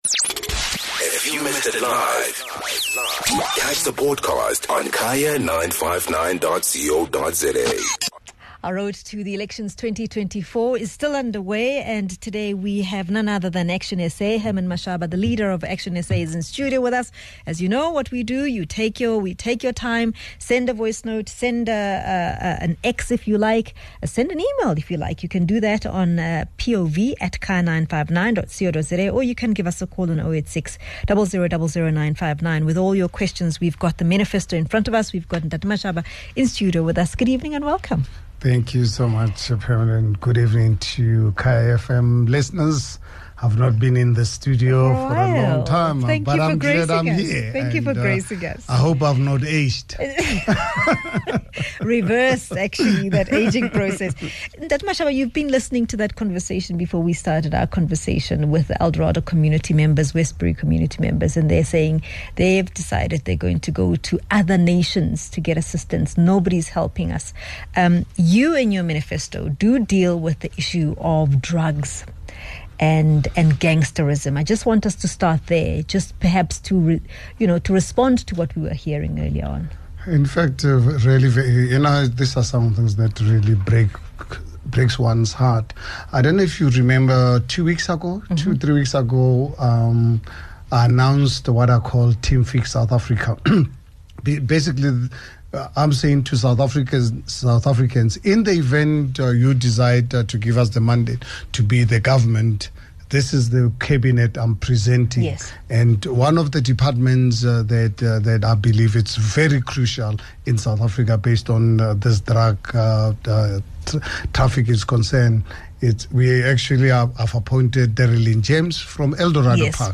ActionSA Leader Herman Mashaba returns to the Kaya 959 studios to share the party's manifesto. ActionSA pays particular attention to the drug and substance abuse problem in South Africa.